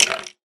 skeletonhurt3